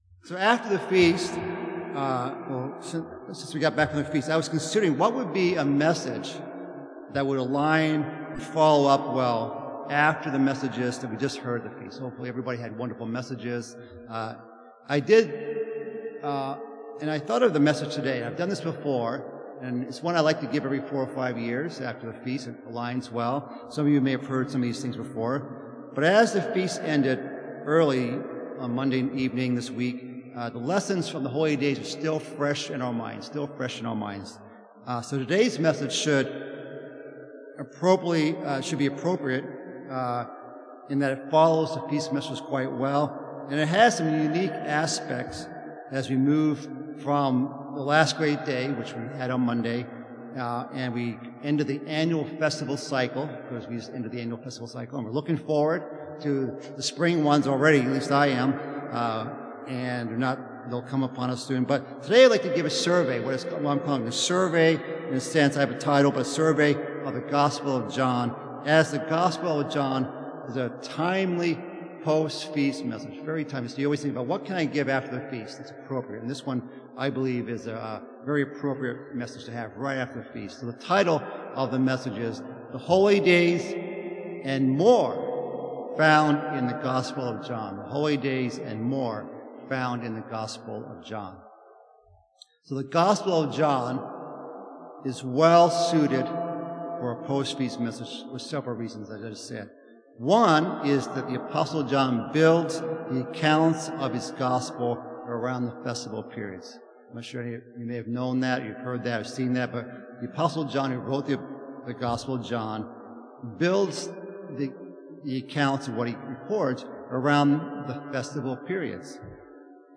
Sermon
Given in Northwest Indiana